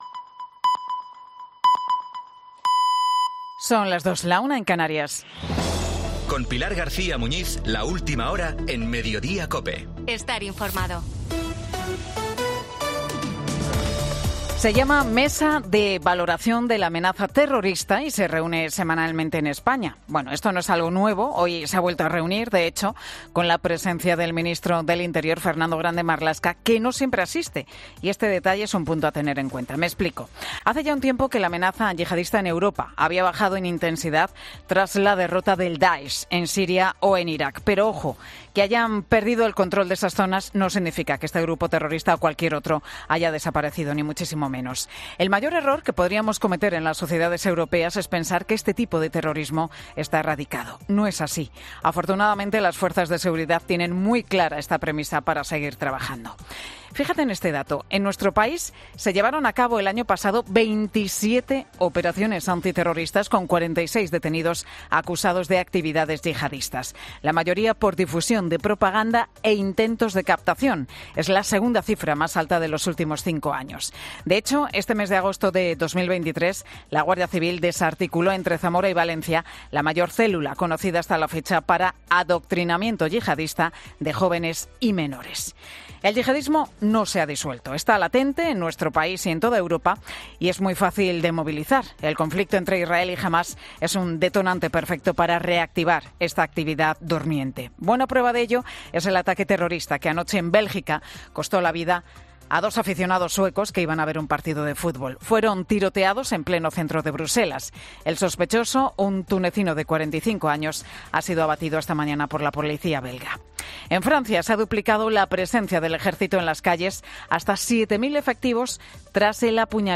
Boletín 14.00 horas del 17 de octubre de 2023 Mediodía COPE